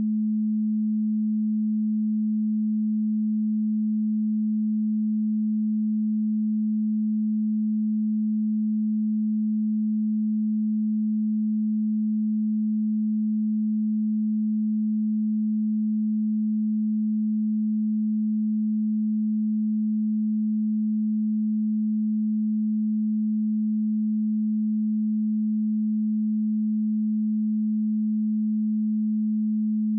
215Hz_-21.dB.wav